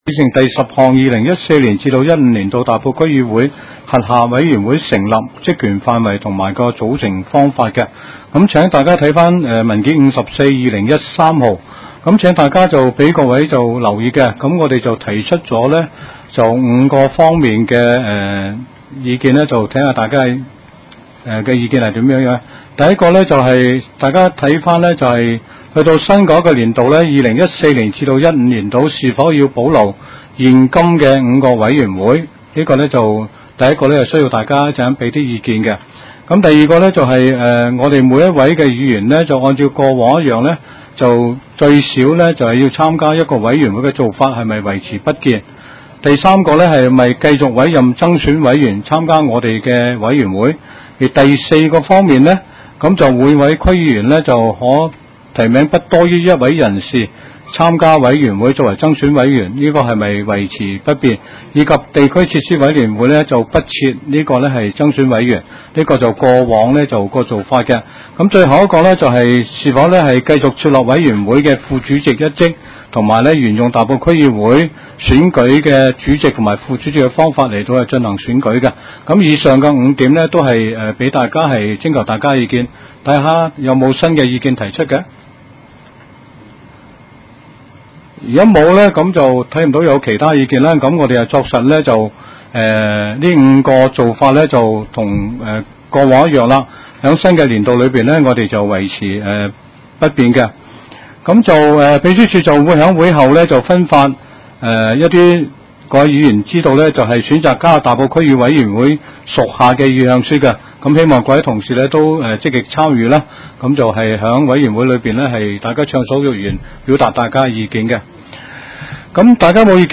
区议会大会的录音记录
大埔区议会秘书处会议室